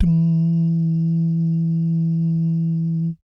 Index of /90_sSampleCDs/ILIO - Vocal Planet VOL-3 - Jazz & FX/Partition B/3 BASS DUMS